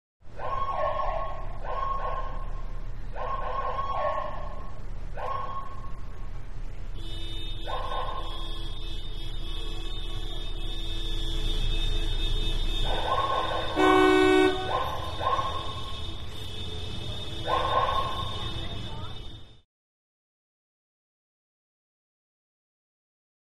Siren; Short Bursts Medium Perspective. In Traffic Jam With Car Horns Medium, One Close.